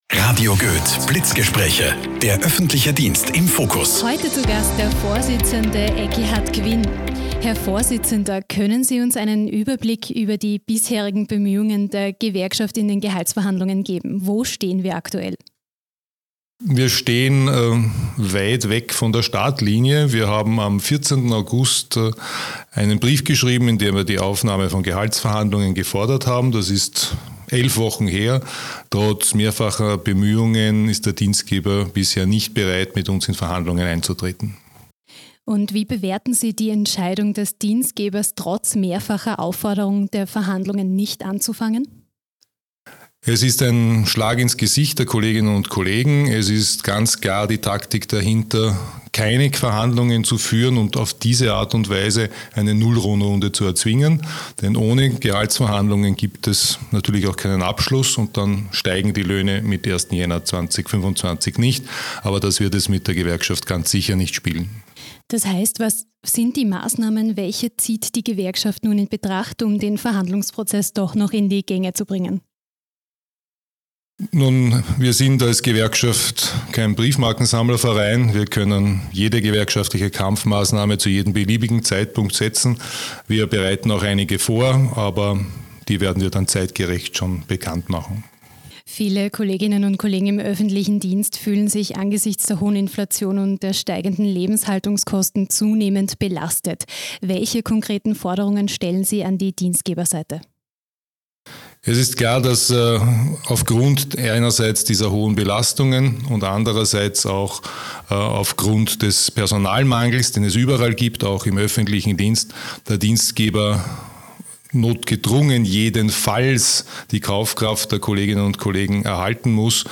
anders aus, sagt er im Radiogespräch.